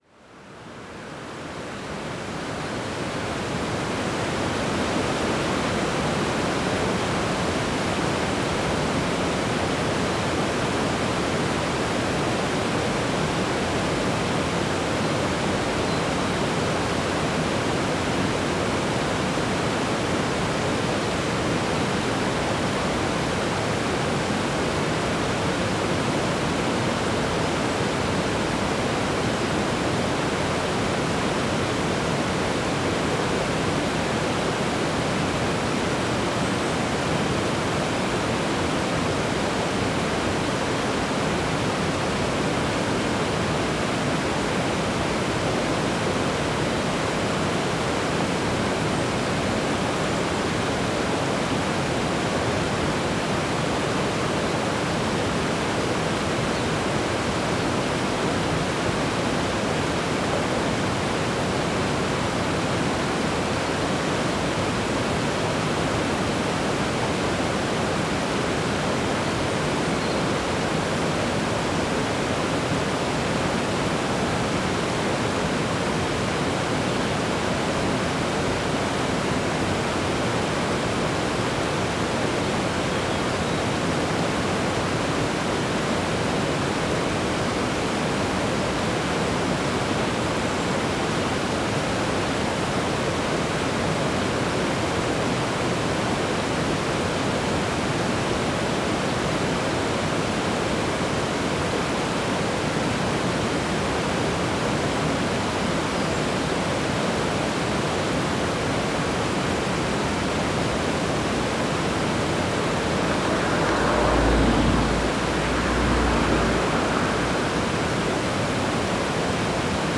Paisagem sonora de escoamento de água junto à ponte na estrada de Sanguinhedo de Côta, Côta a 11 Março 2016.
Numa tarde soalheira de Inverno junto à ponte na estrada de Sanguinhedo de Côta a água desliza fria sobre blocos de granito.
NODAR.00520 – Côta: Escoamento de água junto à ponte na estrada de Sanguinhedo de Côta